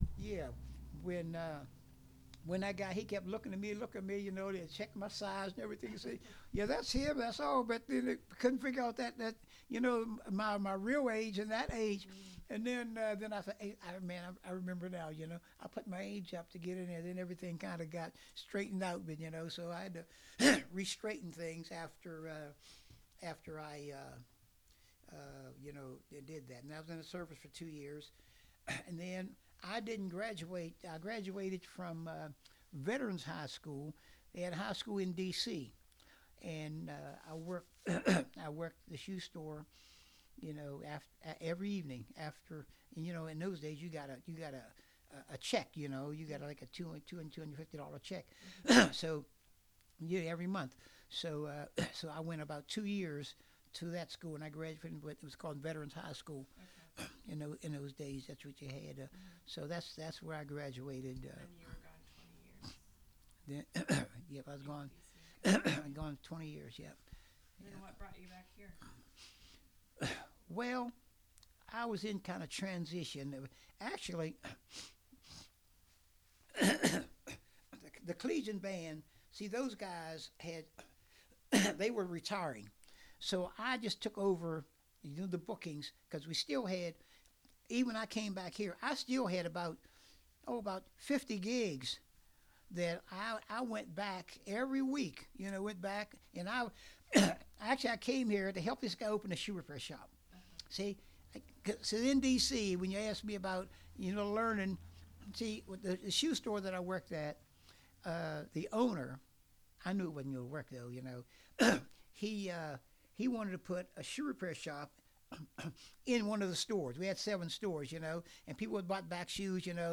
This interview is part of a collection of interviews conducted with Scotts Run natives/residents and/or members of the Scotts Run Museum.